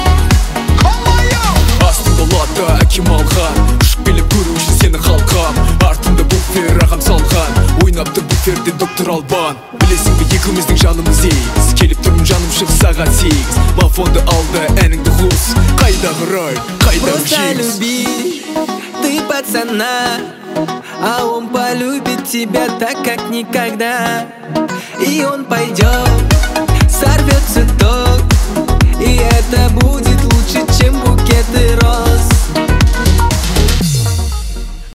• Качество: 192, Stereo
поп
казахские